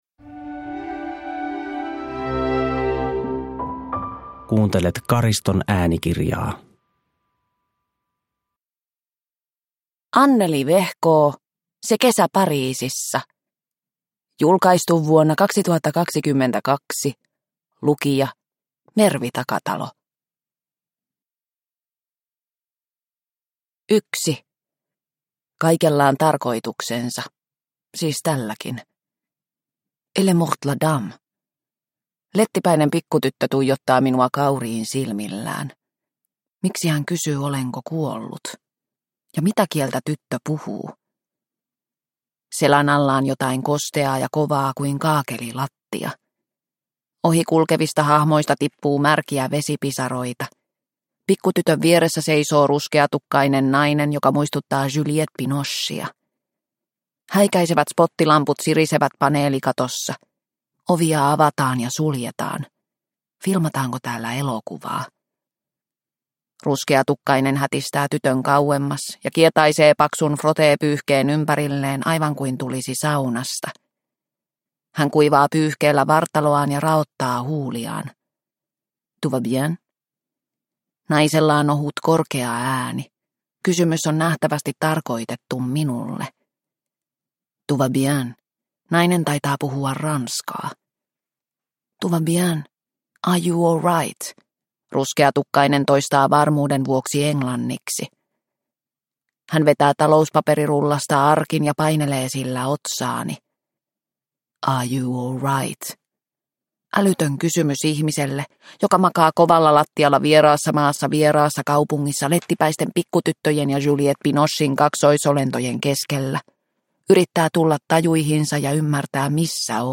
Se kesä Pariisissa – Ljudbok – Laddas ner